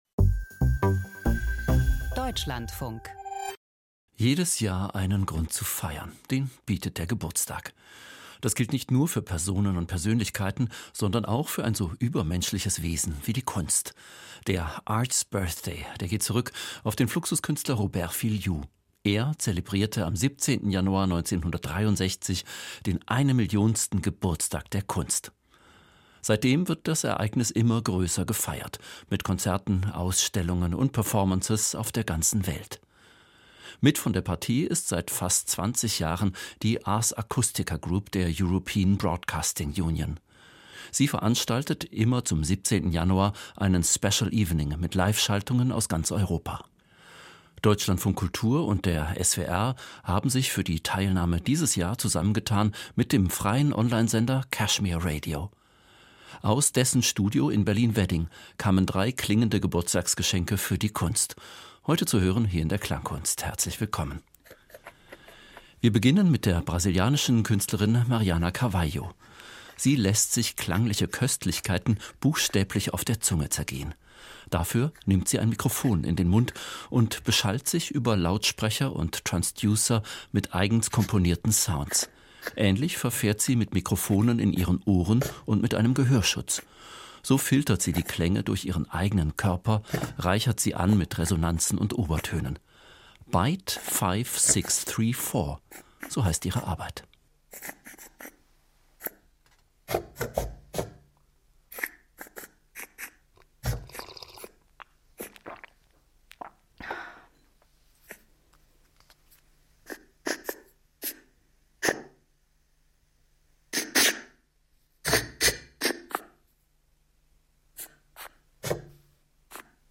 Sound-Art: Ein Fest zu Ehren der Kunst - Art's Birthday 2025